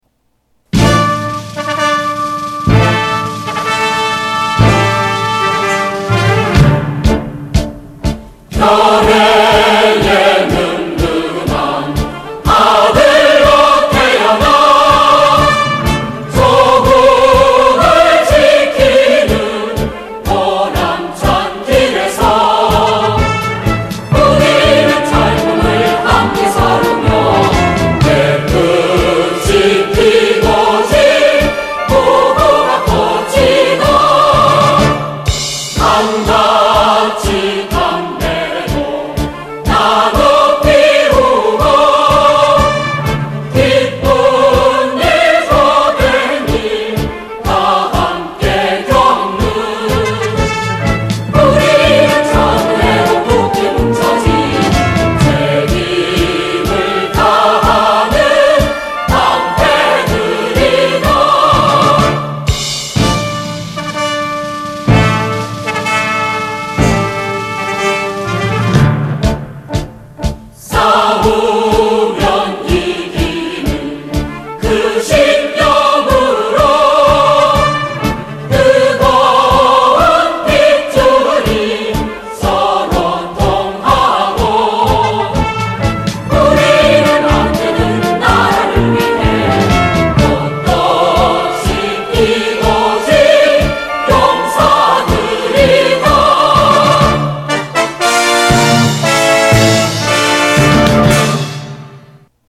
A Military song of Korea